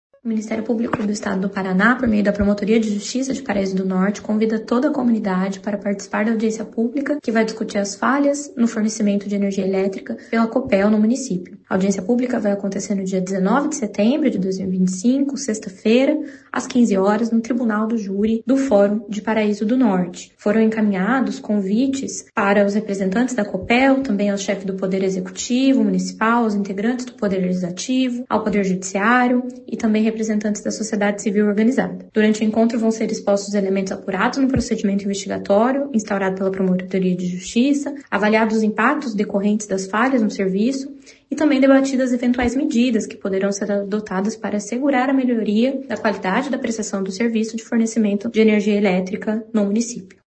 Ouça o que a promotora de Justiça Bruna Britto Martins.